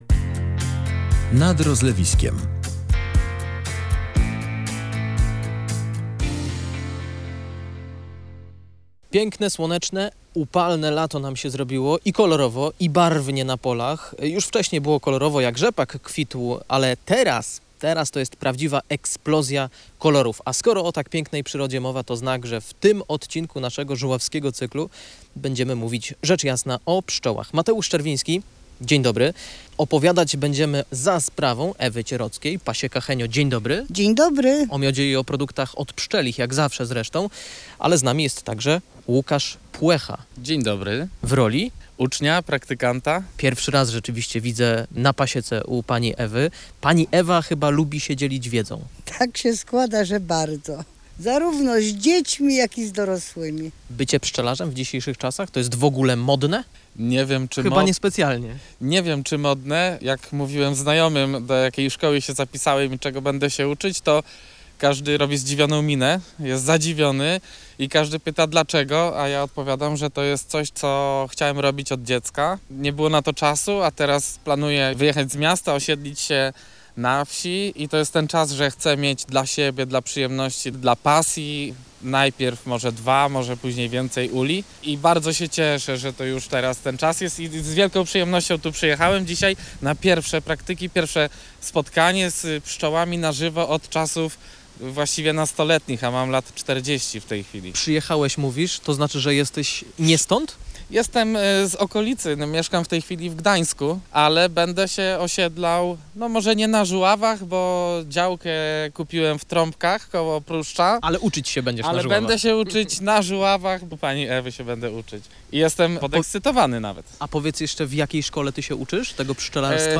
Odwiedzamy pasiekę na Żuławach, gdzie nauka łączy się z pasją